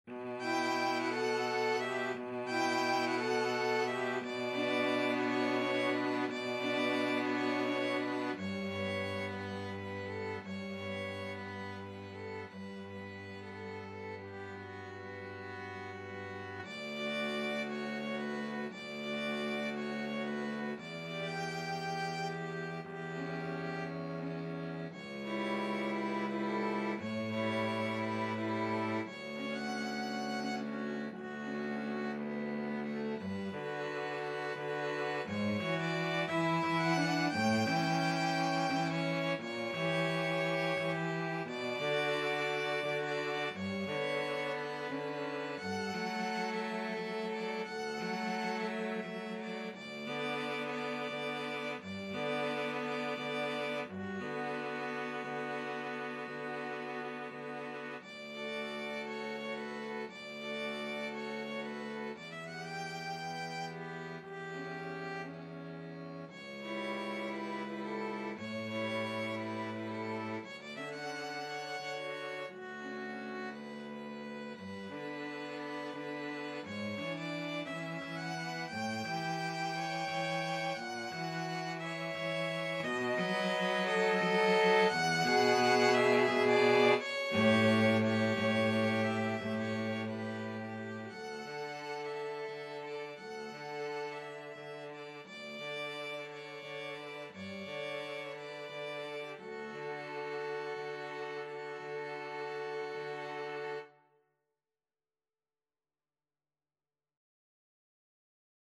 Violin 1Violin 2Cello
6/8 (View more 6/8 Music)
Classical (View more Classical 2-Violins-Cello Music)